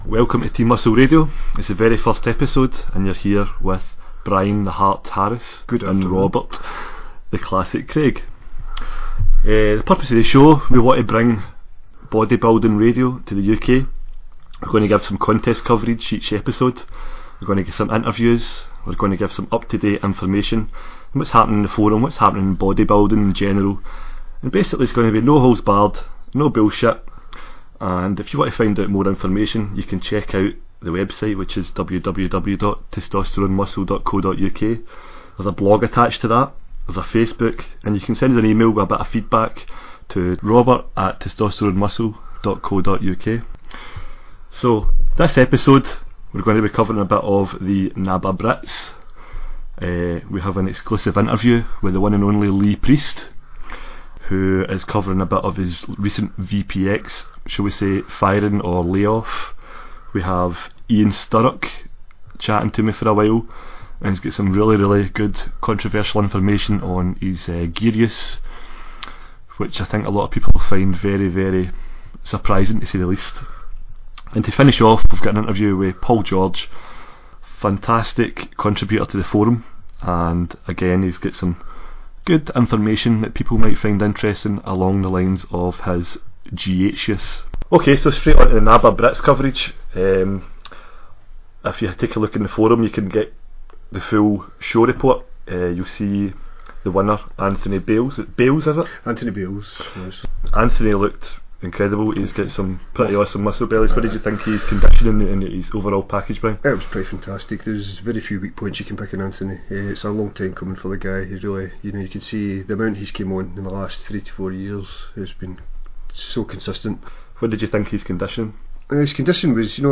* Interview with Lee Priest - Discussion on VPX and a possible return to competition.